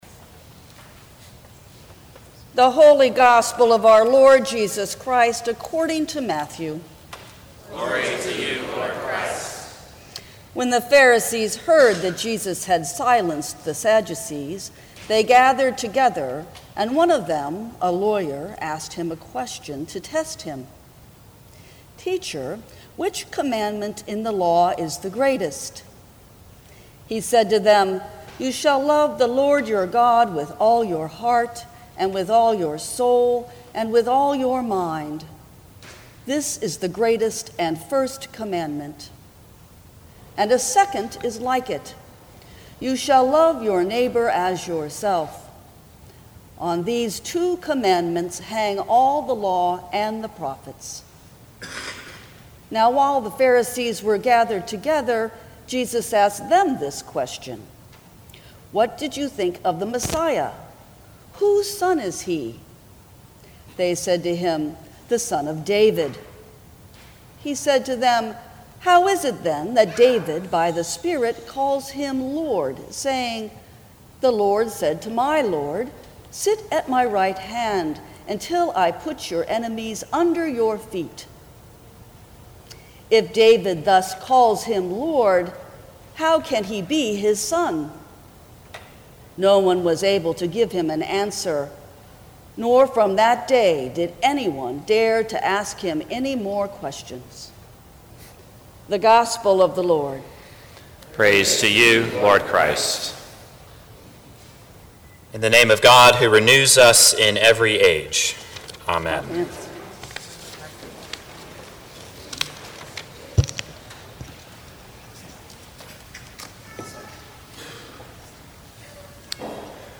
Twenty First Sunday After Pentecost
Sermons from St. Cross Episcopal Church Life with a Living God Oct 29 2017 | 00:15:04 1x 00:00 / 00:15:04 Subscribe Share Apple Podcasts Spotify Overcast RSS Feed Share Link Embed